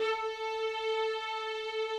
strings_057.wav